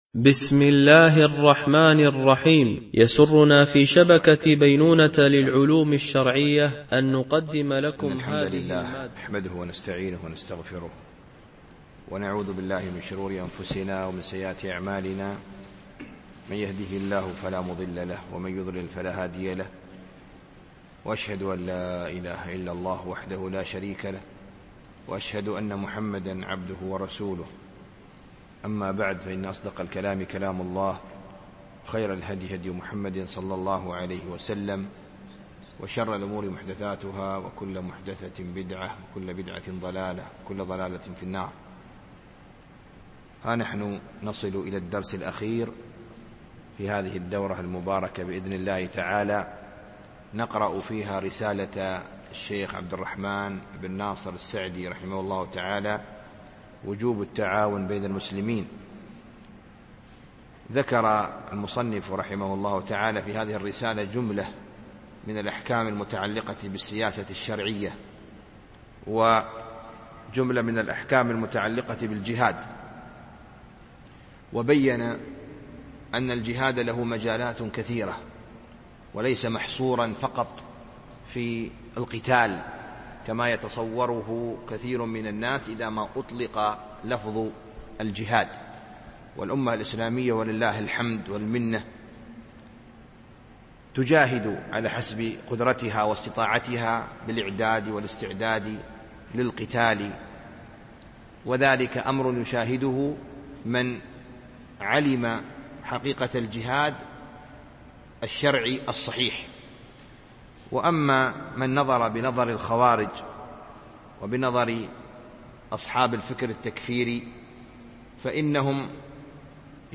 رسالة في وجوب التعاون بين المسلمين لابن السعدي ـ الدرس السادس والأخير